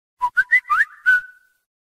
key-delete.mp3